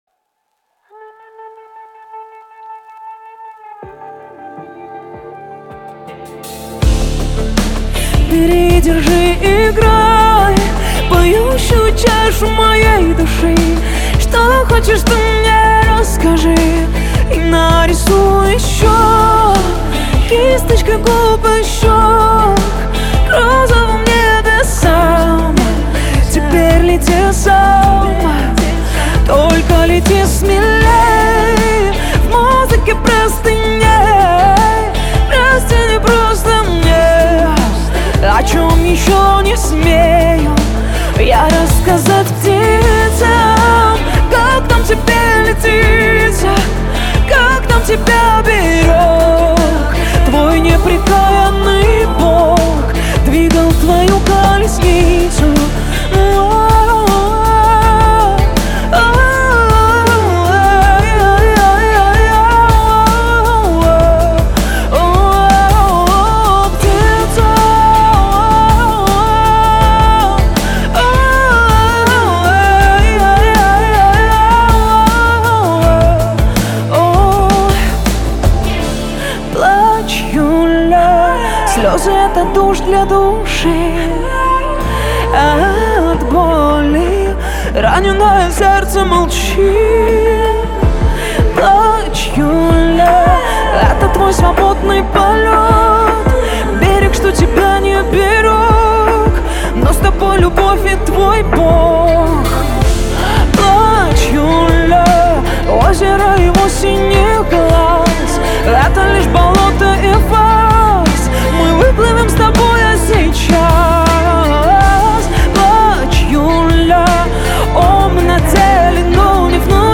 Категории: Русские песни, Поп.